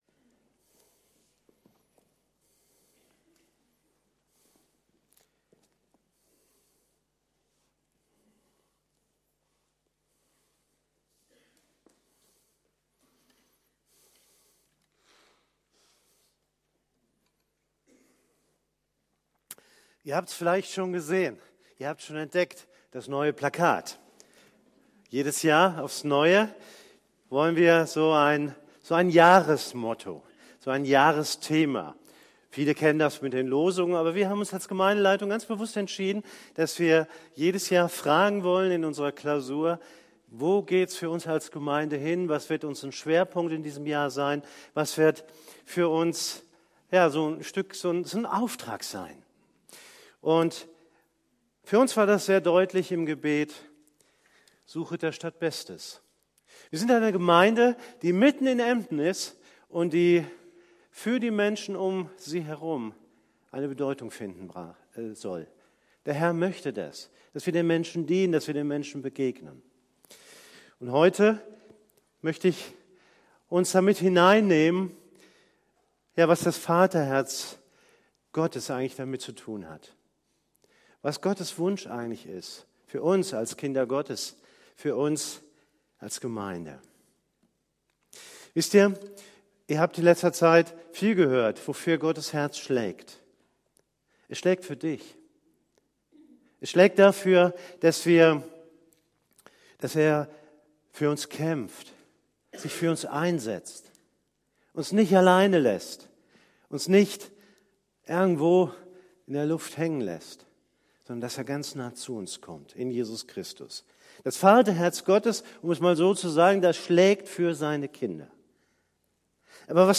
predigt.mp3